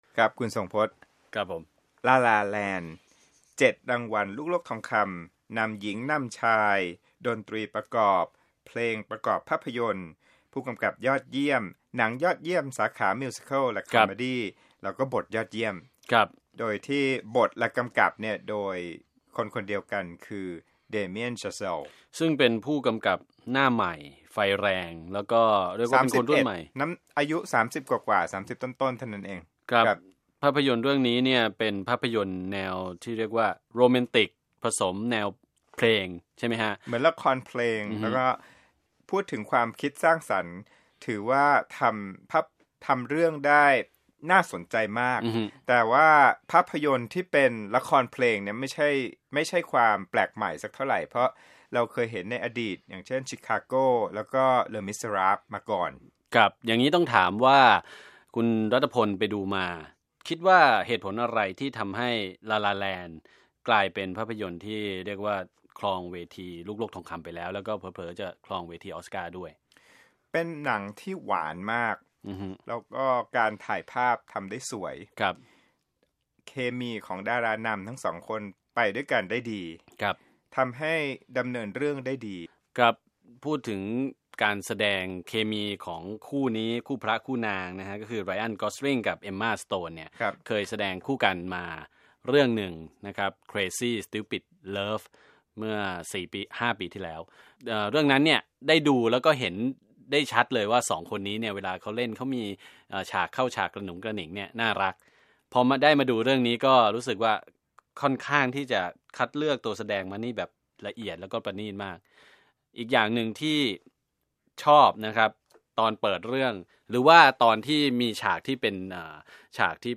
คุยหนัง La La Land